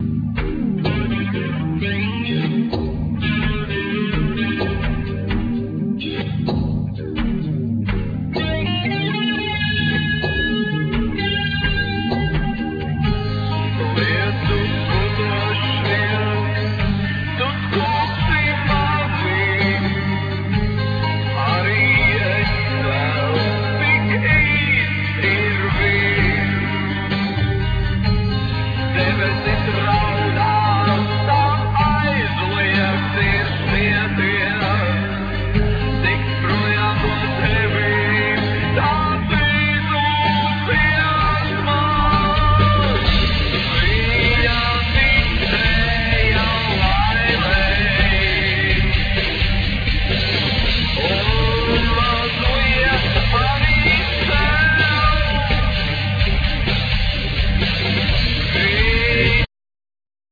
Vocals,Guitar
Bass,Guitar
Drums